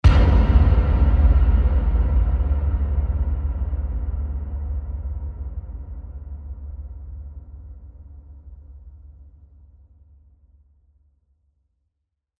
咚惊悚恐怖出场音效免费下载
SFX音效